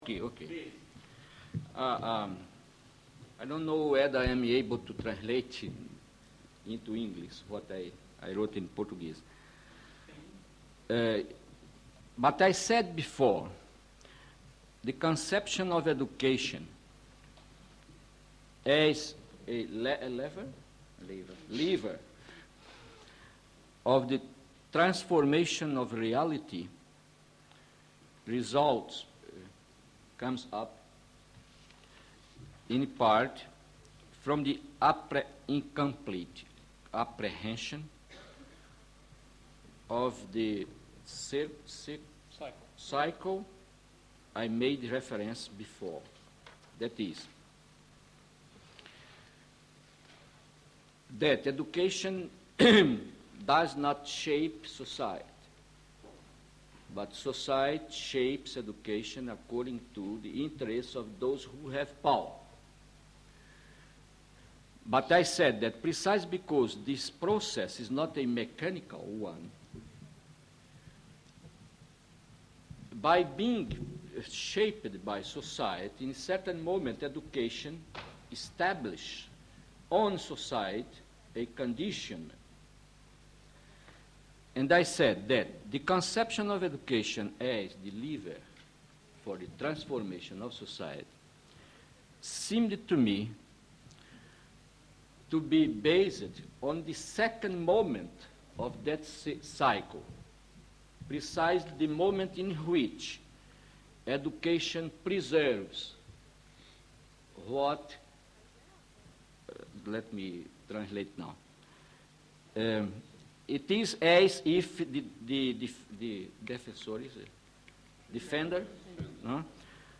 Paulo Freire – Extrait du séminaire Paulo Freire et Ivan Illich – An invitation to conscientization and deschooling: a continuing conversation (6 septembre 1974)
freireillichseminarfreire.mp3